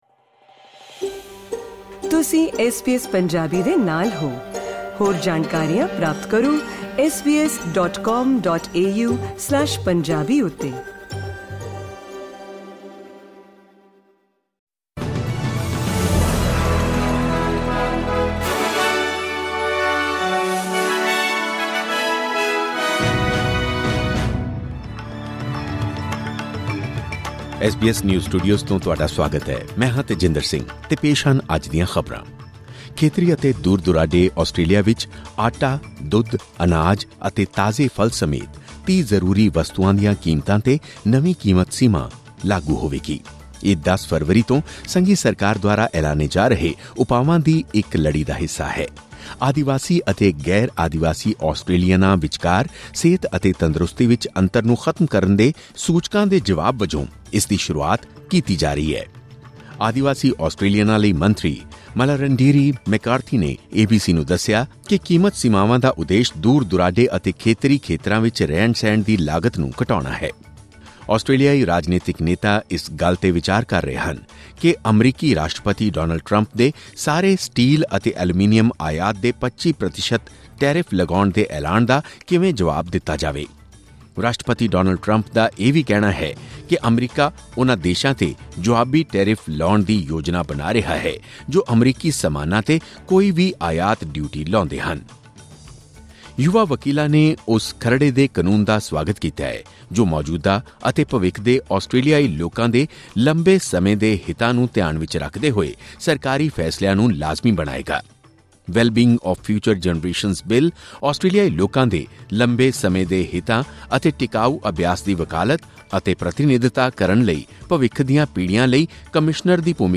ਖਬਰਨਾਮਾ: ਖੇਤਰੀ ਅਤੇ ਰਿਮੋਟ ਆਸਟ੍ਰੇਲੀਆ ਵਿੱਚ 30 ਜ਼ਰੂਰੀ ਵਸਤੂਆਂ 'ਤੇ ਨਵੀਂ ਕੀਮਤ ਸੀਮਾ ਲਾਗੂ